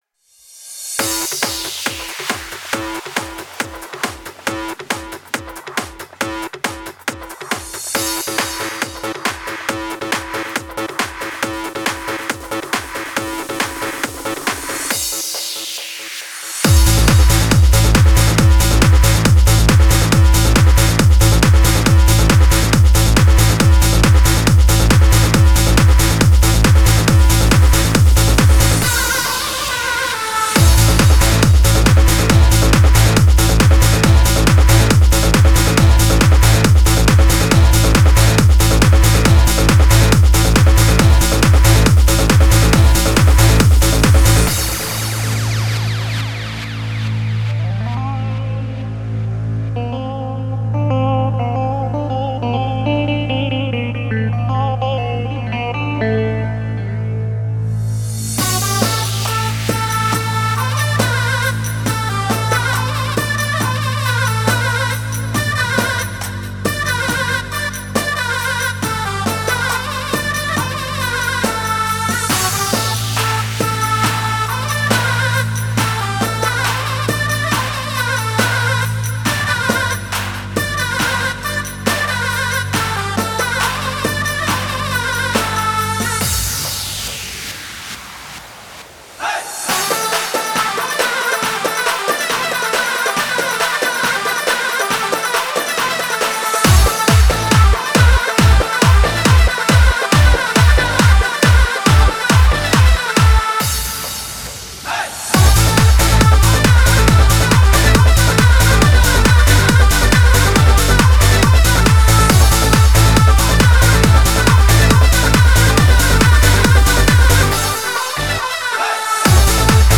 Главная » Музыка » Dance mix